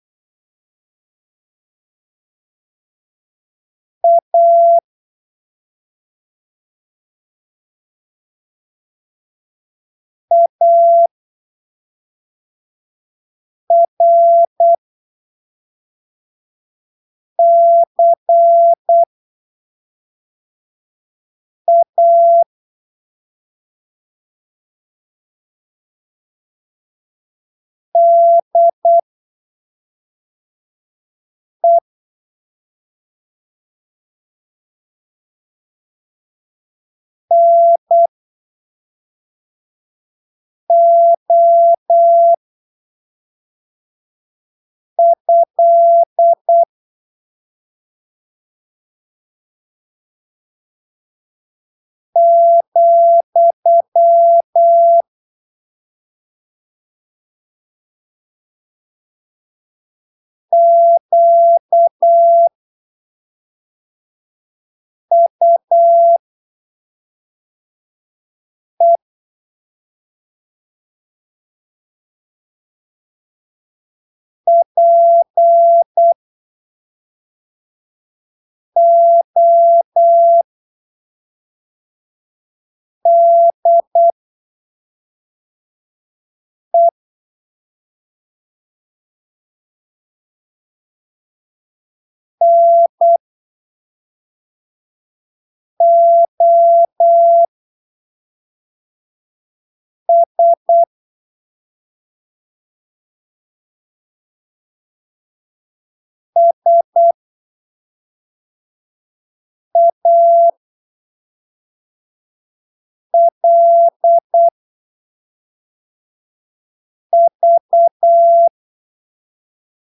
em em uma velocidade aproximada de "8ppm" com um espaçamento
bem generoso entre os caracteres. Você vai encontrar o texto em
Texto CW em áudio  02              Texto correção 02